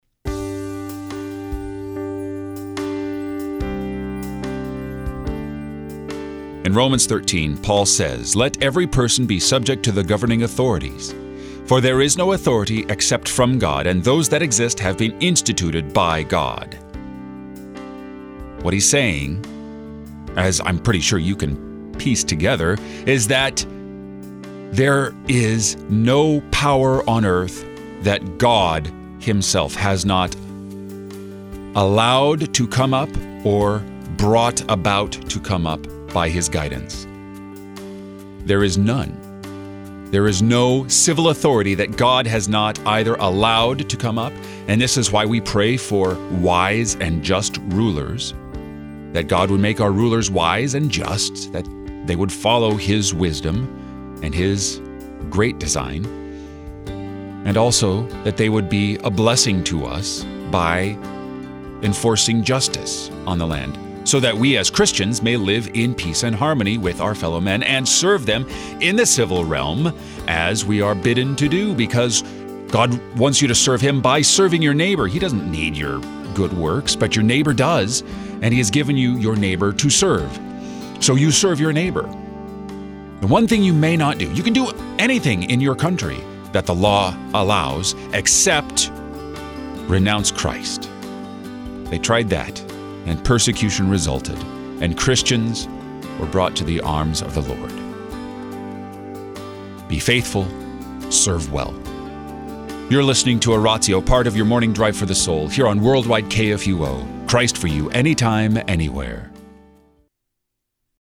give meditations on the day’s scripture lessons.